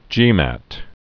(jēmăt)